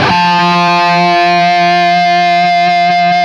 LEAD F 2 CUT.wav